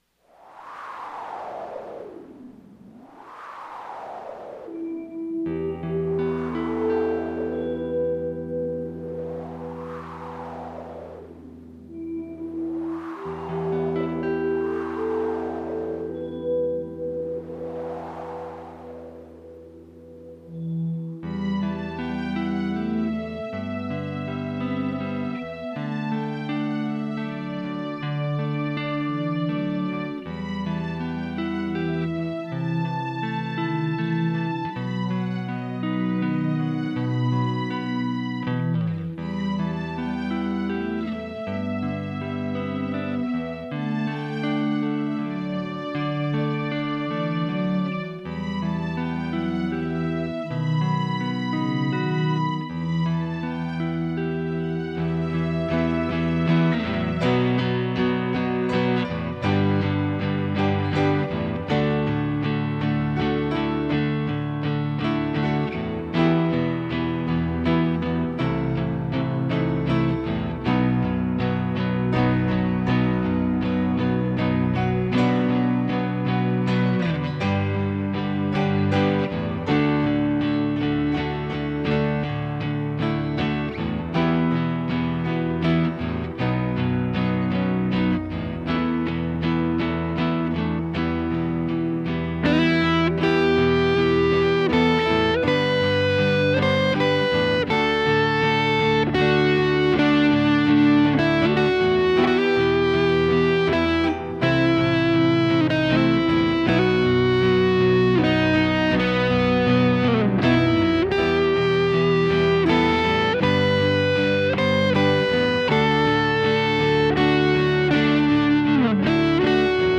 Here's an audio clip of my recent TC-15 combo build:
The Amp's master Volume is cranked and the Cut control is @ 12:00 for both guitar tracks.
The rhythm track was recorded with a Fender Strat, neck pickup, plugged into the LO input of Channel 1 (the EF86 side).
I muted the lead track and mixed the rhythm track up a bit during the first verse to illustrate the clean sound of the TC-15. Note how the amp transitions nicely from "jazzy" clean to "crunchy" as the picking hand becomes more aggressive during the chorus.
The lead track (starts on second verse) uses a PRS Santana III (a Les Paul type guitar with humbuckers), neck pickup, plugged into the HI input of Channel 1.
I appreciate how this amp "sings" when turned up and has nice sustain without getting "mushy" sounding.
Behringer GI100 DI Box/Speaker Simulator with an 8 ohm "dummy load" attached (I don't have a cab/speaker yet :) ) ->
PC running Guitar Tracks Pro software.
Some awesome tones there!